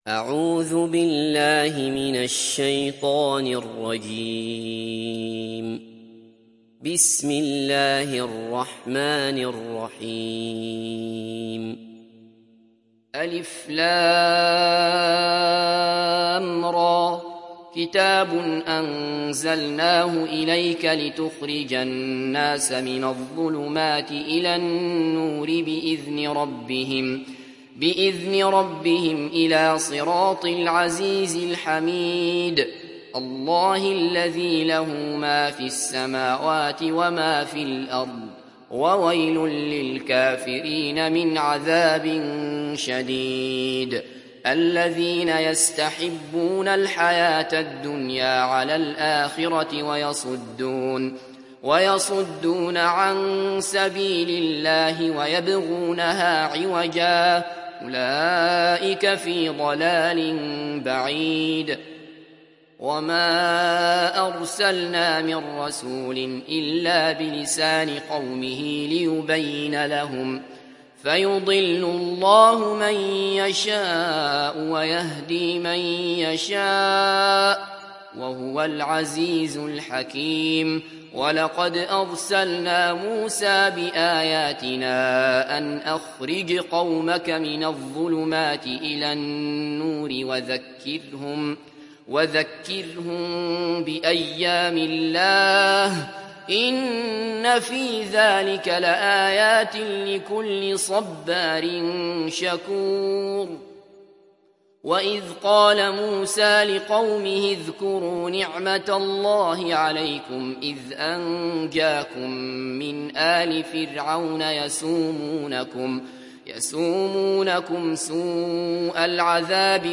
Surat Ibrahim Download mp3 Abdullah Basfar Riwayat Hafs dari Asim, Download Quran dan mendengarkan mp3 tautan langsung penuh